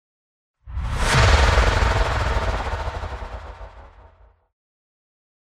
На этой странице собраны звуки внезапного появления: резкие переходы, неожиданные всплески, тревожные сигналы.